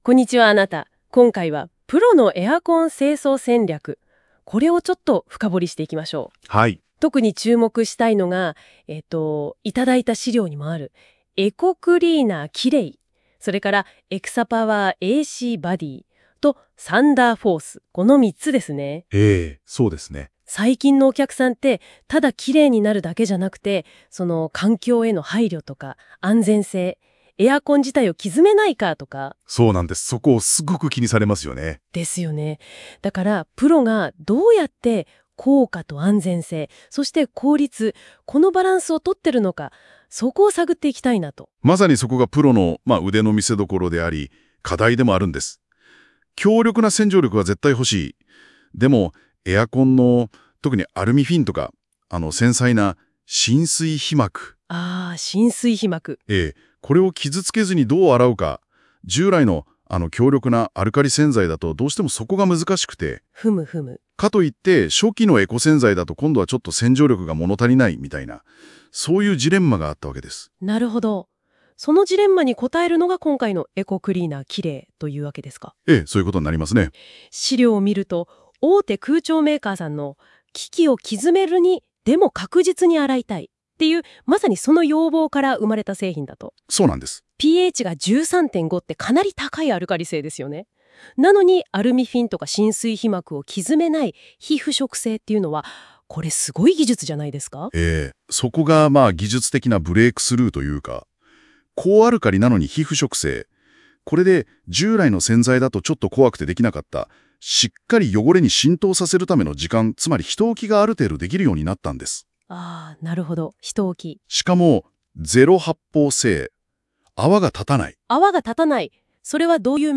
AIパーソナリティが清掃業界を探求します
以下の音声ファイルはAIによる自動生成のため、読み上げに不自然な箇所があったり、内容に不整合が生じている場合があります。